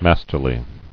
[mas·ter·ly]